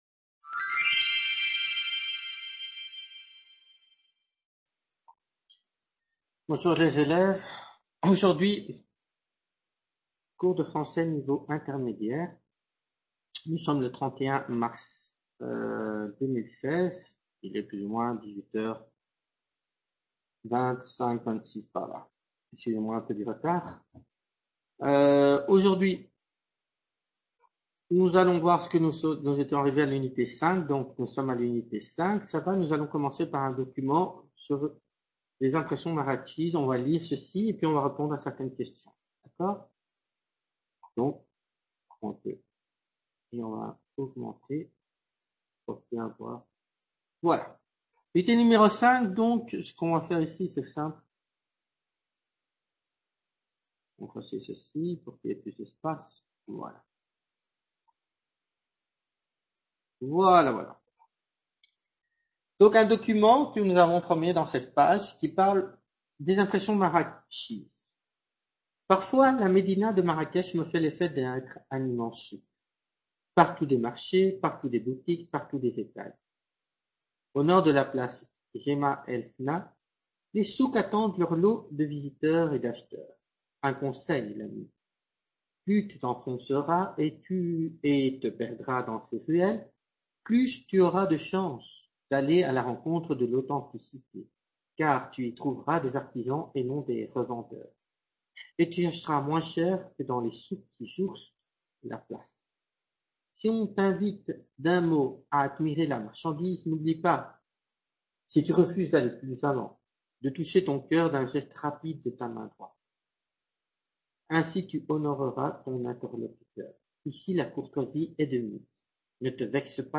Clase de Francés Nivel Intermedio 31/03/2016 | Repositorio Digital